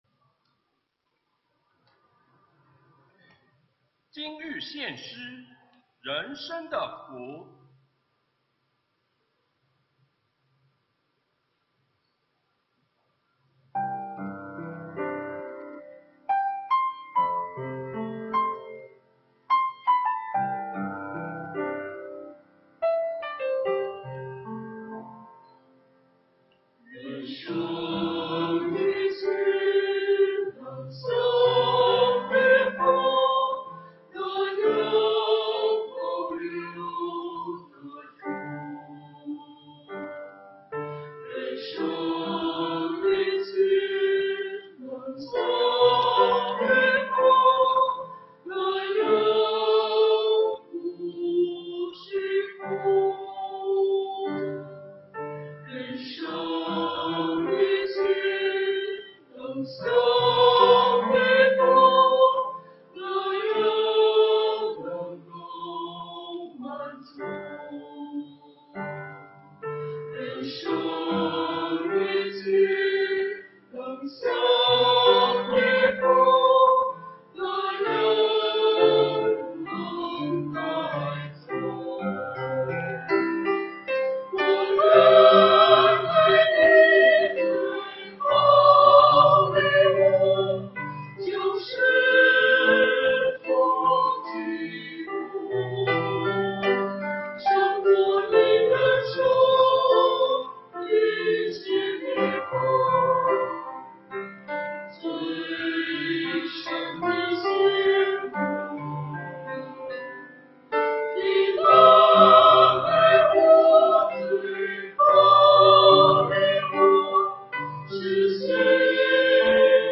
团契名称: 大诗班 新闻分类: 诗班献诗 音频: 下载证道音频 (如果无法下载请右键点击链接选择"另存为") 视频: 下载此视频 (如果无法下载请右键点击链接选择"另存为")